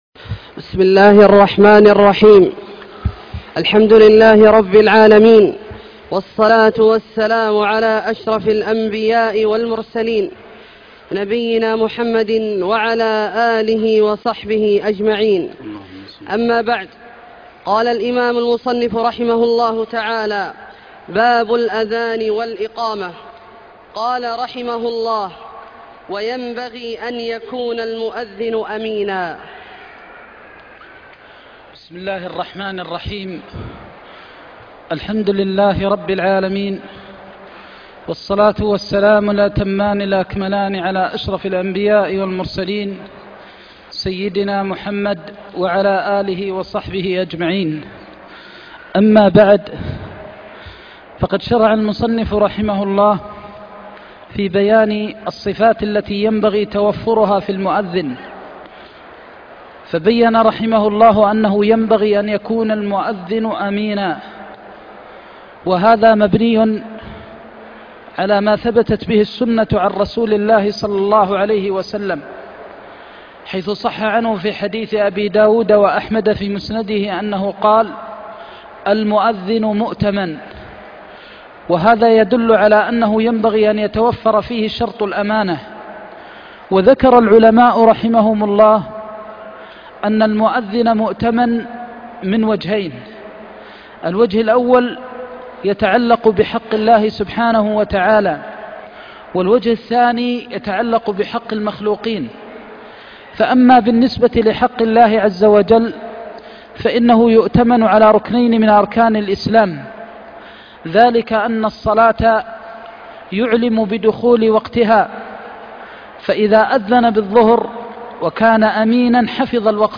درس عمدة الفقه (2) - تابع كتاب الصلاة